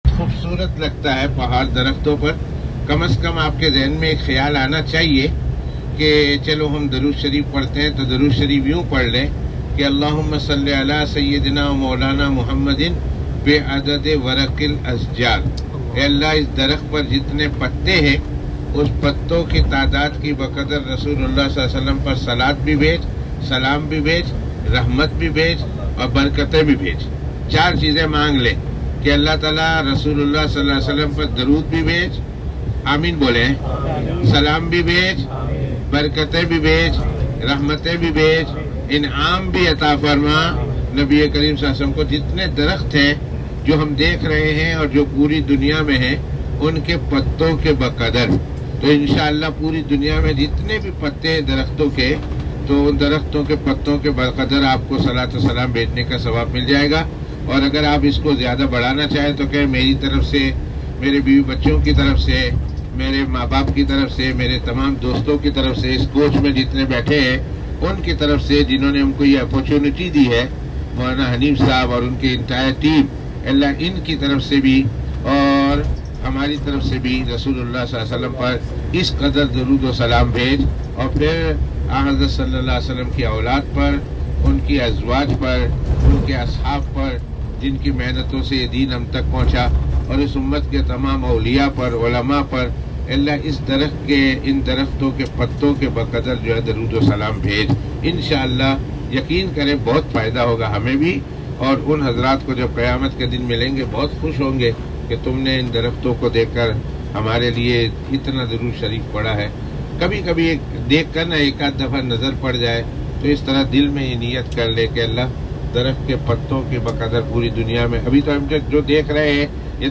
As we travelled along, we heard some more pearls of wisdom, which I would like to share with the readers and listeners.